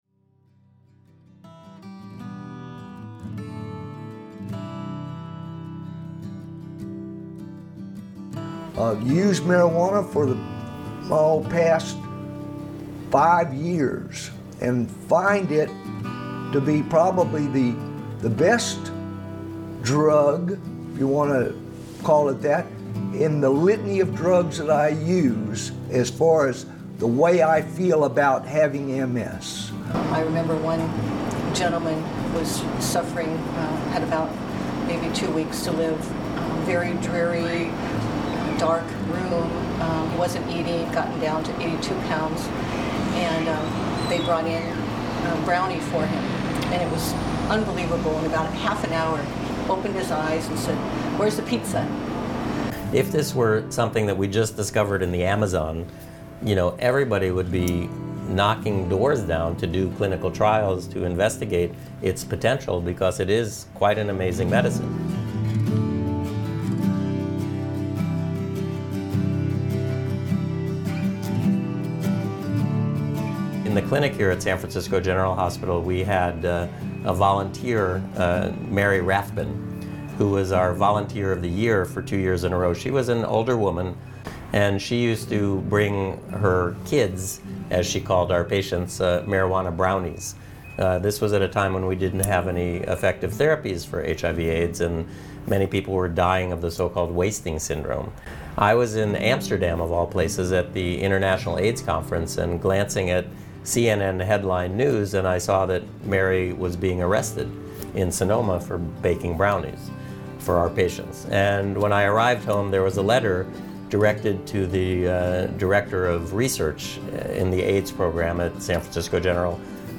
We talked to a doctor, a pharmacist, and a patient to get three firsthand perspectives on medical cannabis.